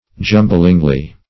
jumblingly - definition of jumblingly - synonyms, pronunciation, spelling from Free Dictionary Search Result for " jumblingly" : The Collaborative International Dictionary of English v.0.48: Jumblingly \Jum"bling*ly\, adv. In a confused manner.